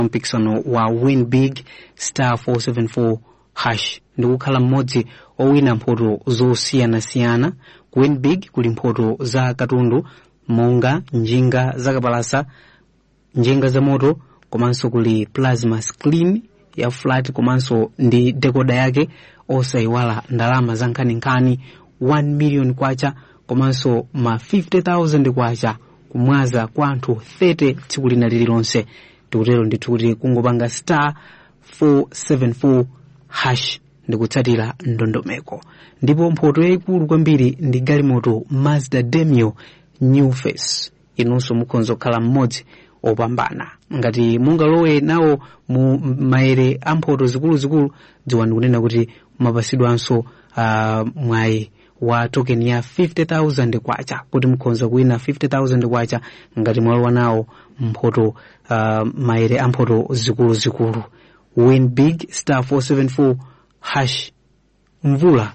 Stand A Chance To Win Live Read